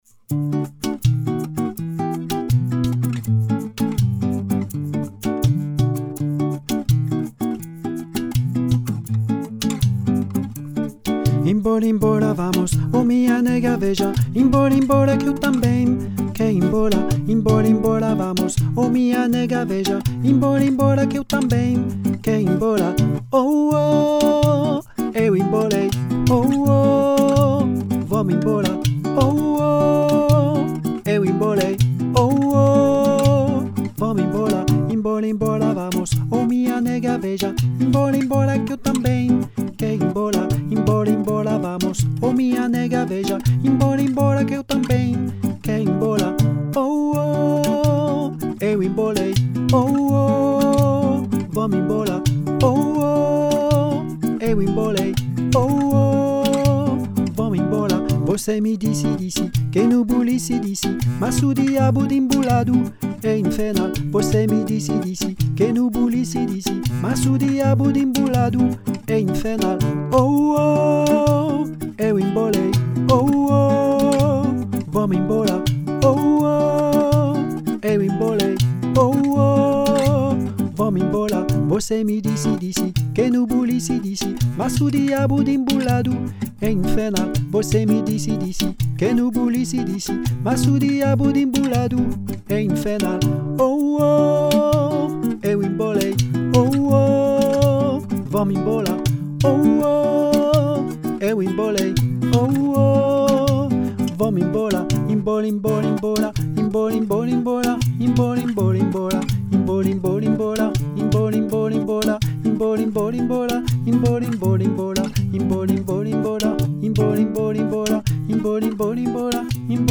Voix 1